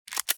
手枪格洛克换弹装上弹夹.mp3